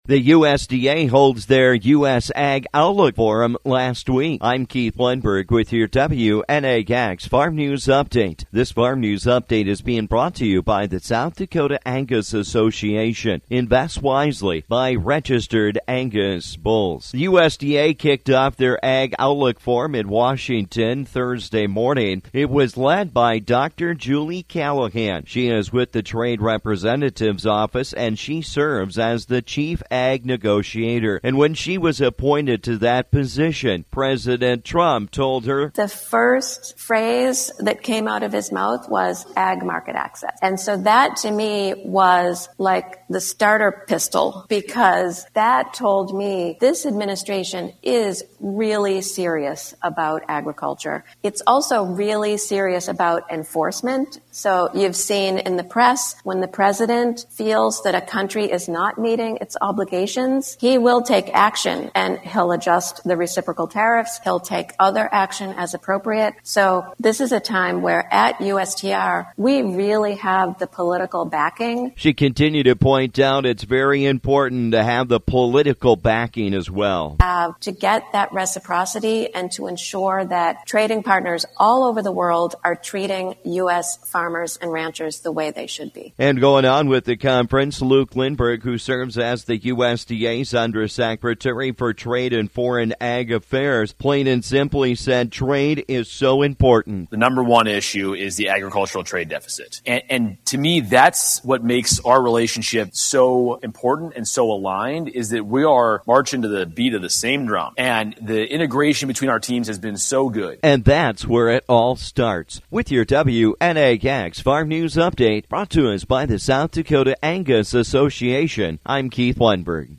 The USDA’s Ag Outlook Forum wrapped up Friday Afternoon, here’s some information about the forum with some guest speakers.